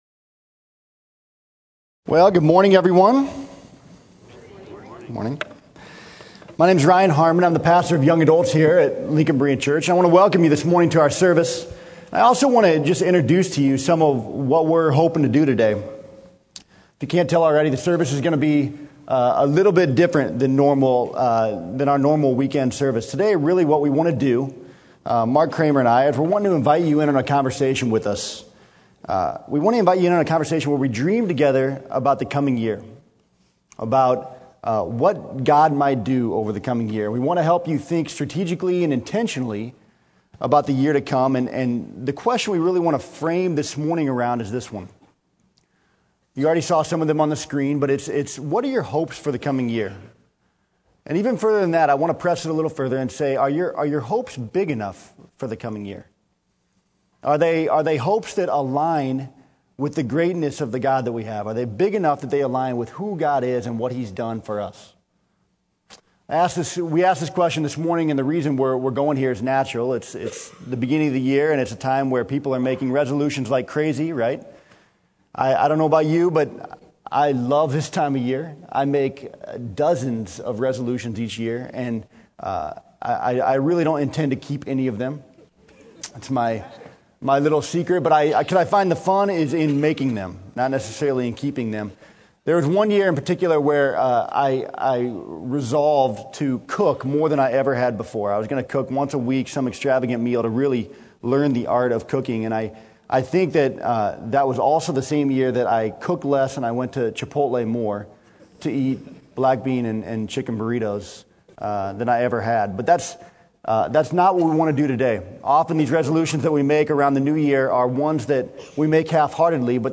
Sermon: Make Every Effort